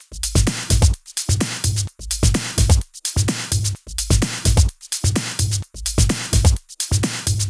Index of /VEE/VEE Electro Loops 128 BPM
VEE Electro Loop 023.wav